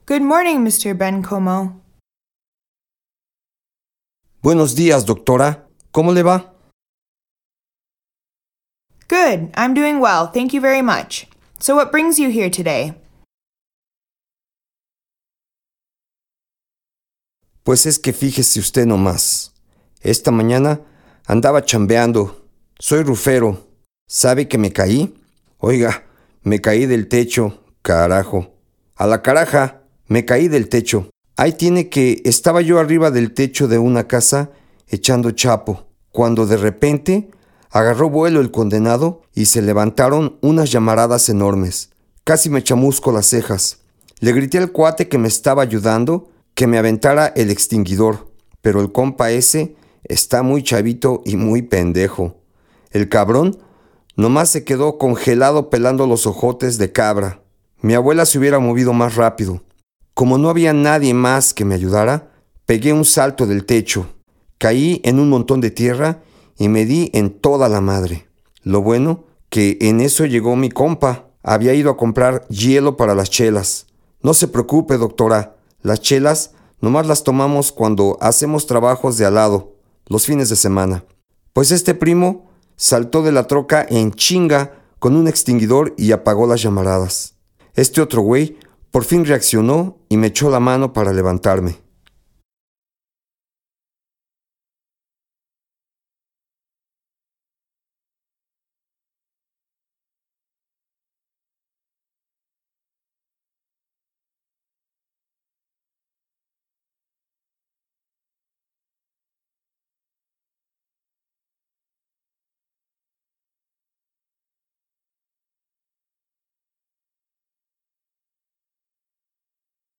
Genre: Educational.
VCI-Practice-Dialogue-02-Sprained-Foot-EN-SP.mp3